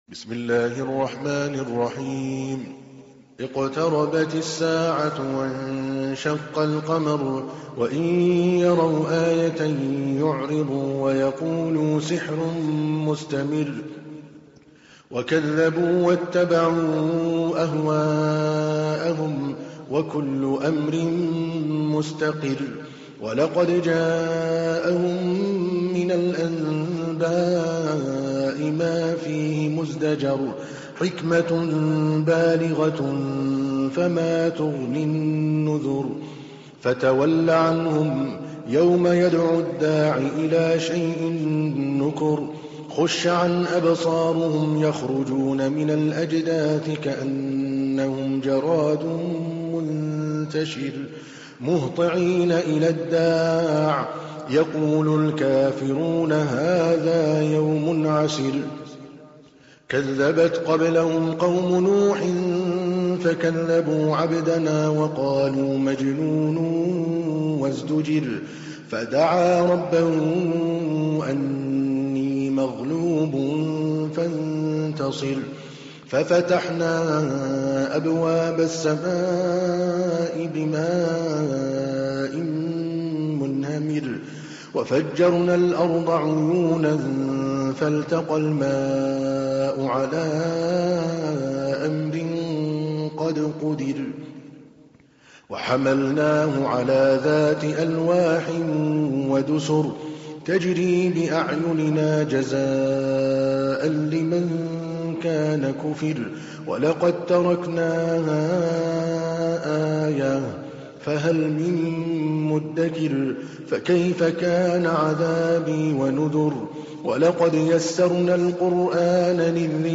تحميل : 54. سورة القمر / القارئ عادل الكلباني / القرآن الكريم / موقع يا حسين